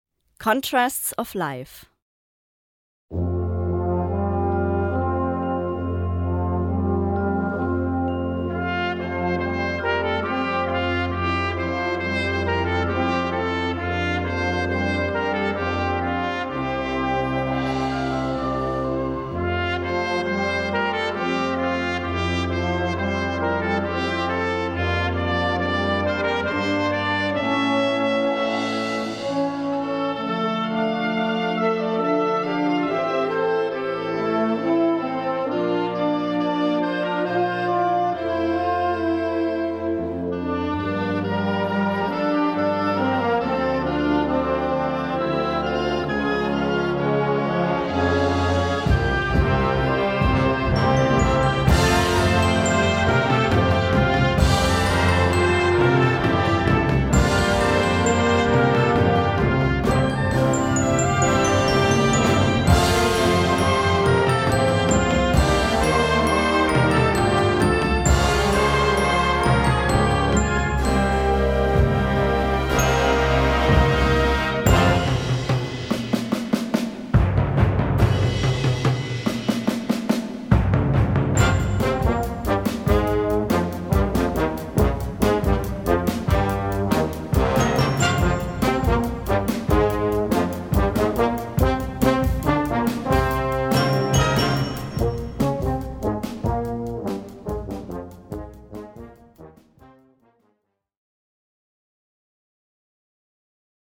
Gattung: Overture for Concert Band
Besetzung: Blasorchester
Rhythmisch, moderne Originalkomposition.